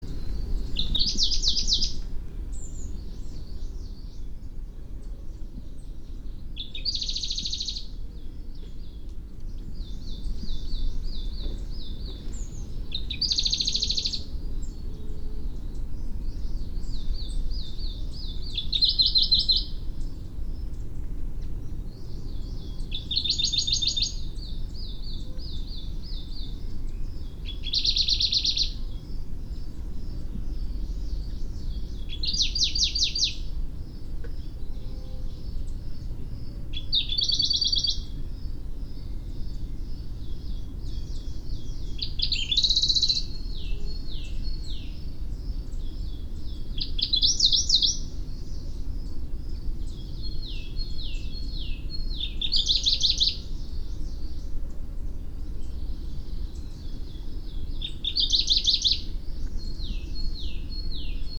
Some Seicercus Warblers in Yunnan, China
In addition, there was another species with quite a different song, including trills.
1. Song, This is recorded 22 April at about 2500 m. Several other individuals were singing similarly nearby, some of which were also sound recorded.